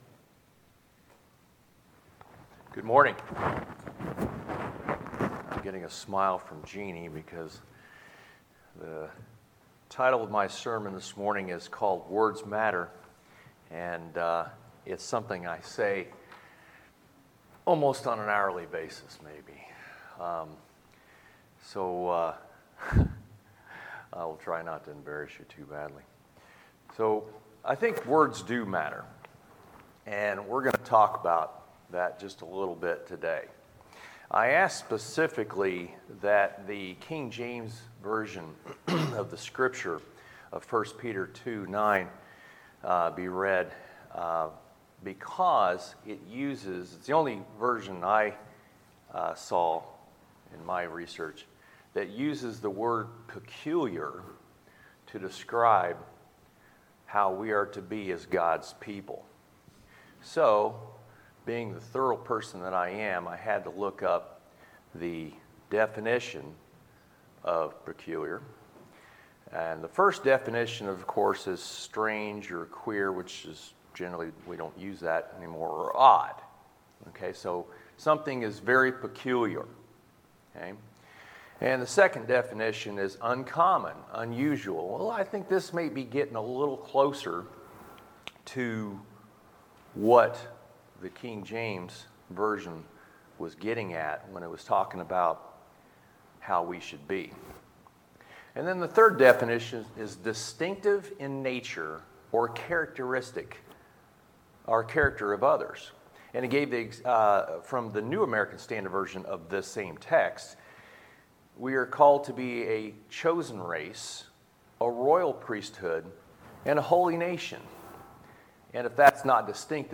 Sermons, June 21, 2020